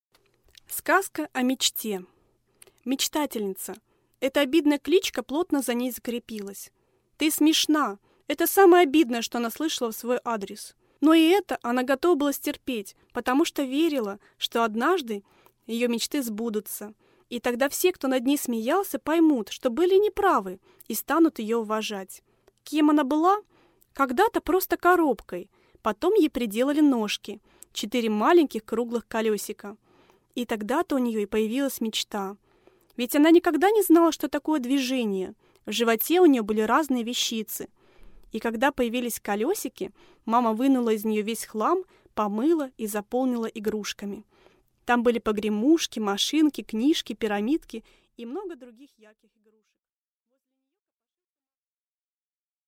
Аудиокнига Сказка о мечте | Библиотека аудиокниг
Прослушать и бесплатно скачать фрагмент аудиокниги